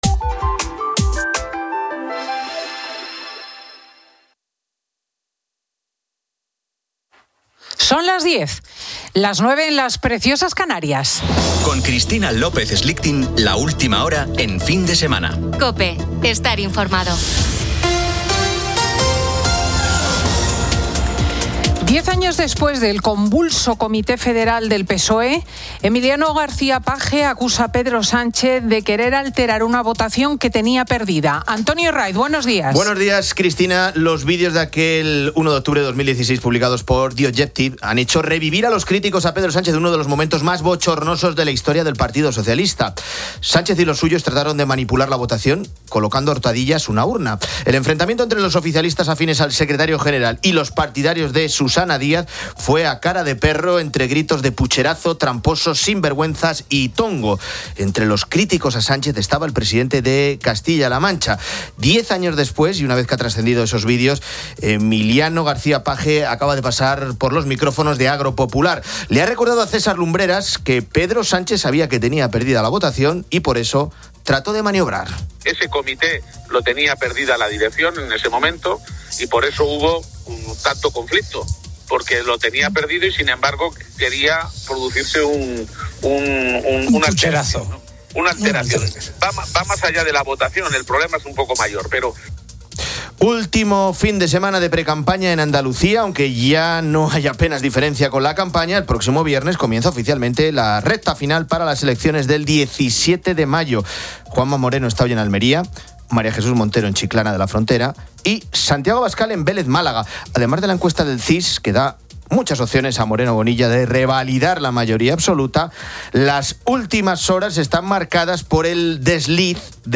Fin de Semana 10:00H | 25 ABR 2026 | Fin de Semana Editorial de Cristina López Schlichting.